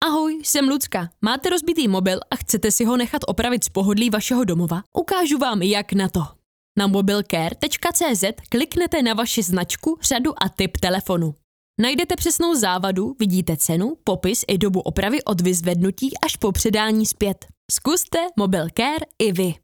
Ženský reklamní voiceover do jedné minuty
Natáčení probíhá v profesiálním dabingovém studiu.